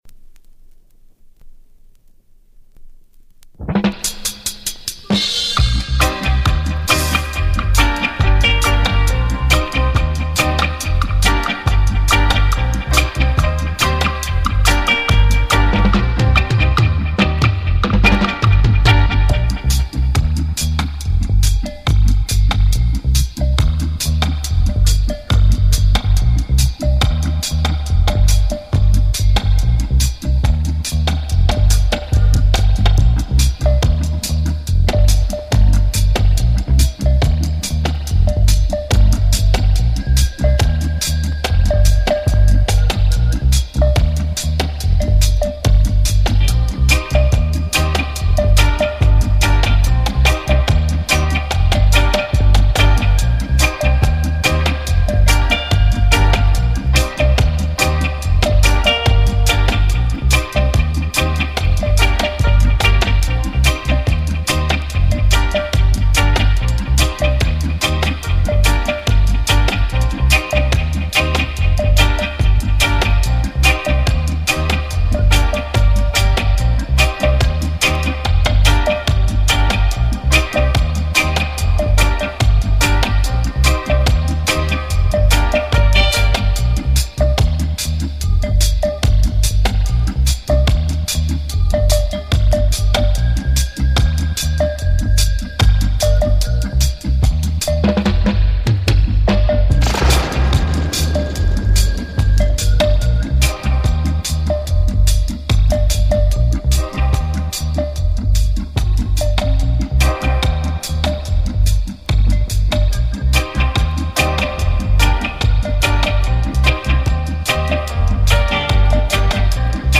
Listen , enjoy and share pòsitive reggae vibes.